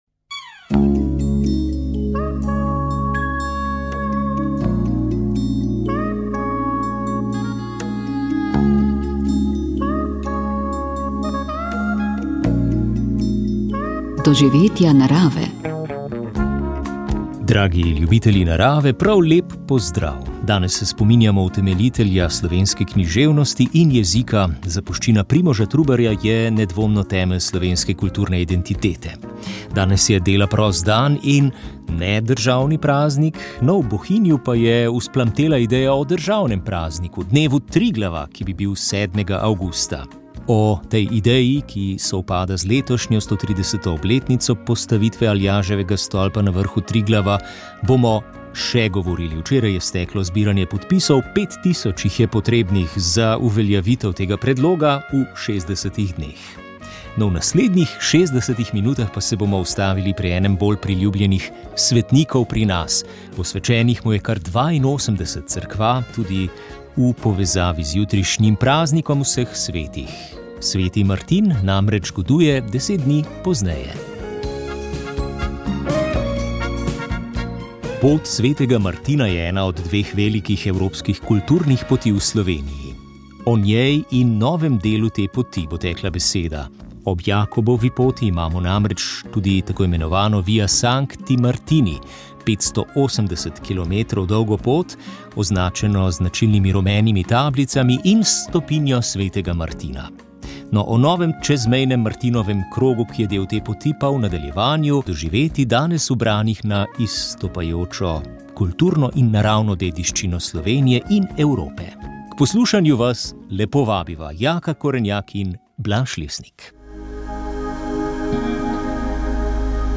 V tokratni oddaji Spoznanje več, predsodek manj je bil naš gost v studiu